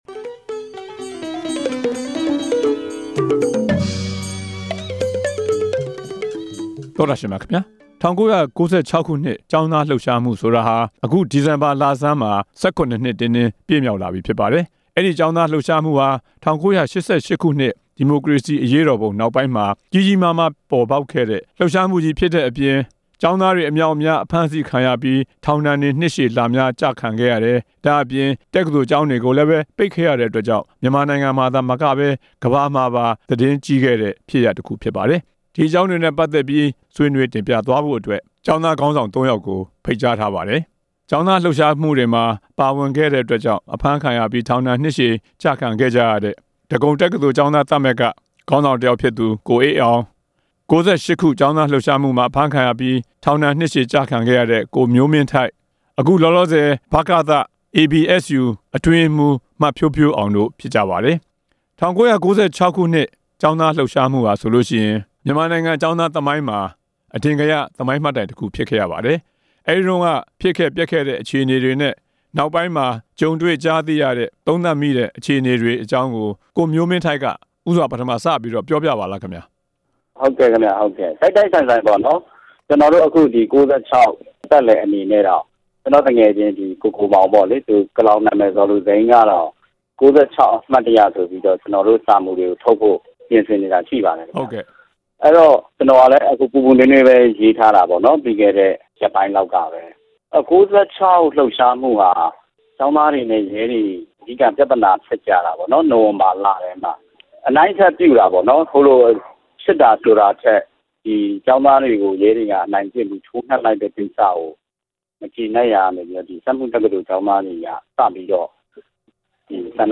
၁၉၉၆ခု-ဒီဇင်ဘာလ ကျောင်းသားလှုပ်ရှားမှု နဲ့ ပတ်သက်ပြီး ဆွေးနွေးချက်